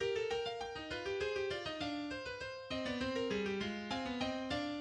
The fugue is for keyboard and in three voices, with regular countersubjects.[8][28] This excerpt opens at last entry of the exposition: the subject is sounding in the bass, the first countersubject in the treble, while the middle-voice is stating a second version of the second countersubject, which concludes with the characteristic rhythm of the subject, and is always used together with the first version of the second countersubject.
The false entry occurs in the alto, and consists of the head of the subject only, marked in red. It anticipates the true entry of the subject, marked in blue, by one quarter note.